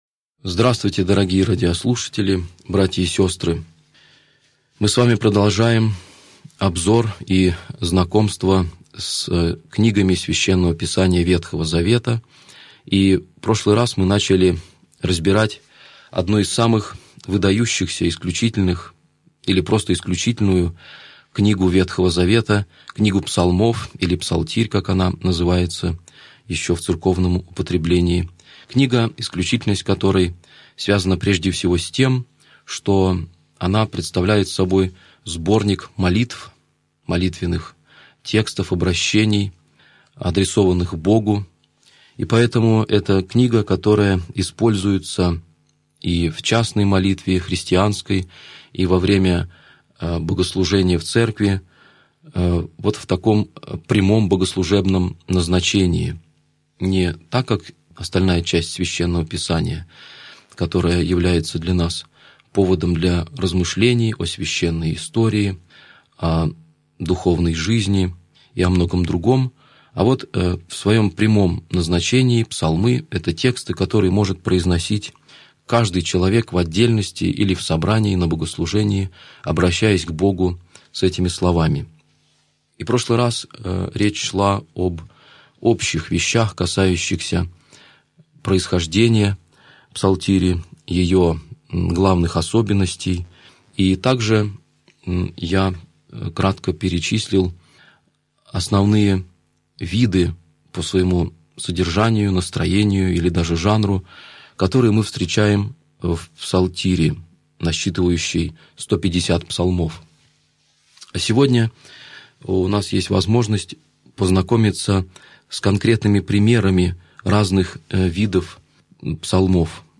Аудиокнига Лекция 31. Псалмы плача | Библиотека аудиокниг